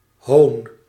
Ääntäminen
IPA: /ɦoːn/